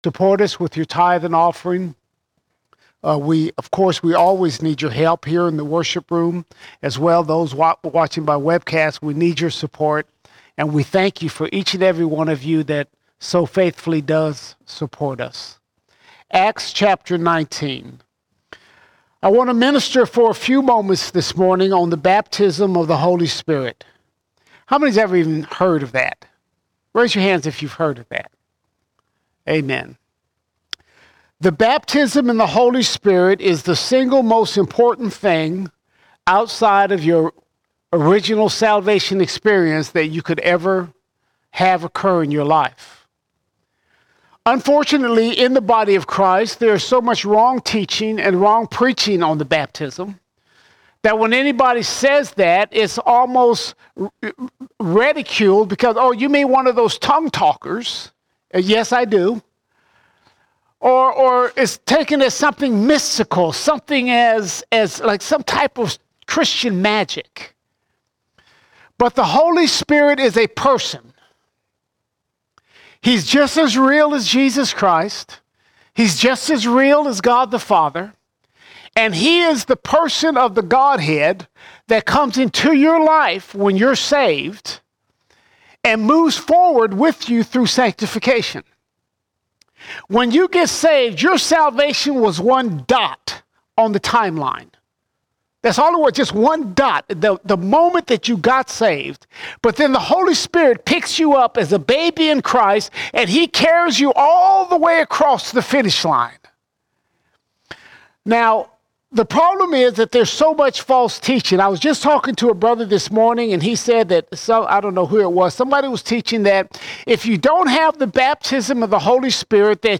2 December 2024 Series: Sunday Sermons Topic: Holy Spirit All Sermons The Holy Spirit The Holy Spirit The Bible says there is a second work of grace where God fills us with the Holy Spirit.